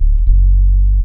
BASS 5 114-L.wav